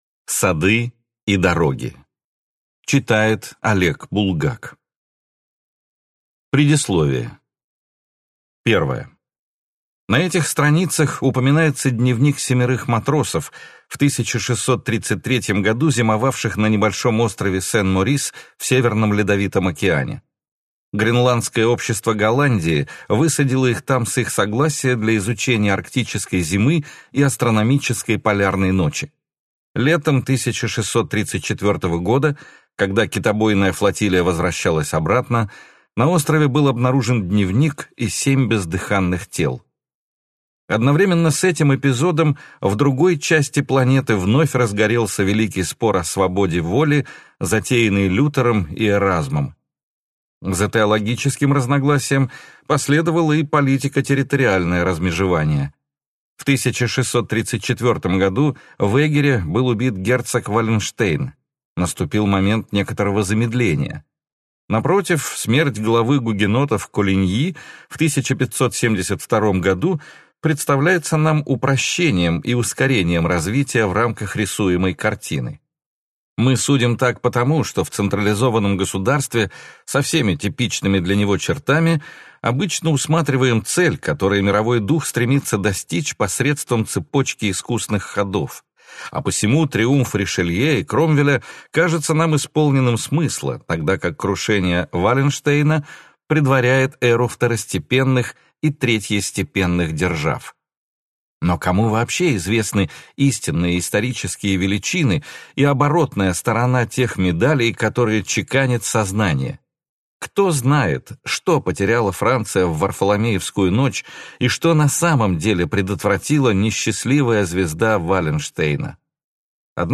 Аудиокнига Сады и дороги | Библиотека аудиокниг